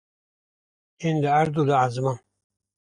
Uitgespreek as (IPA) /ʕɛzˈmɑːn/